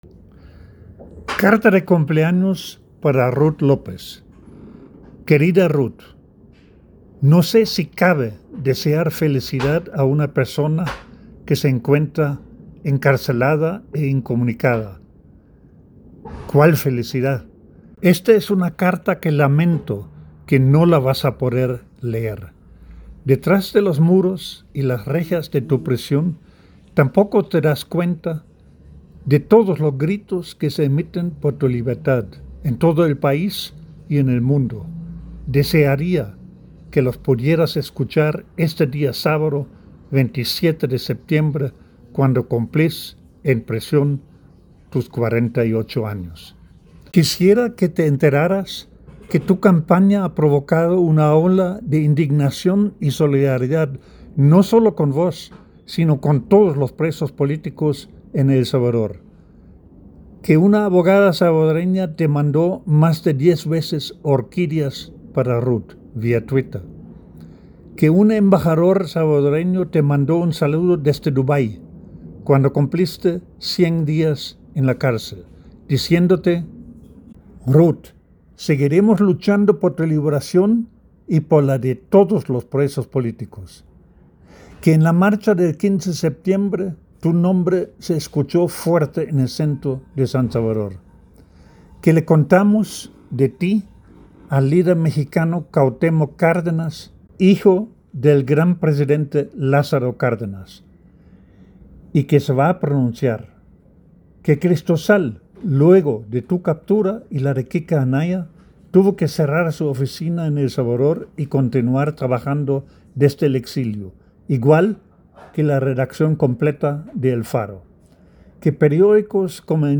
En la voz del autor